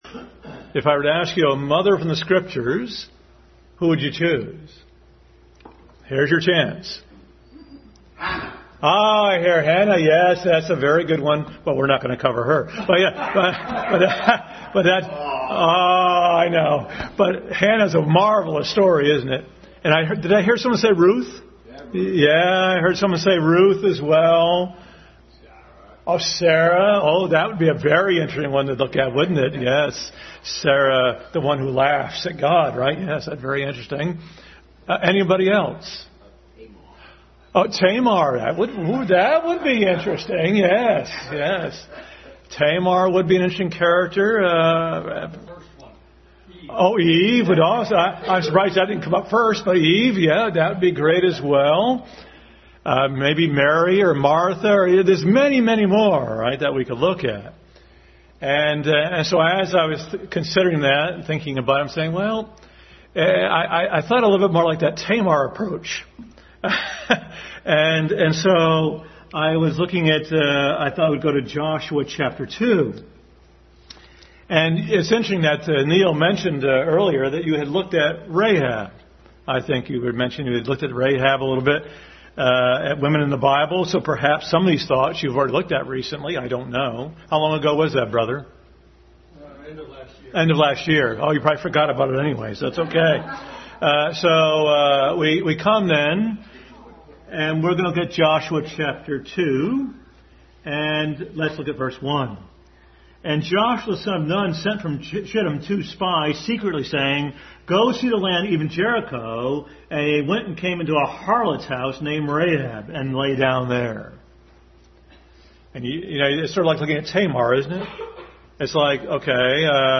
Rahab Passage: Joshua 2:1-24, 6:17, Hebrews 11:31, Ruth 4:18-21, Matthew 1:1-5 Service Type: Sunday School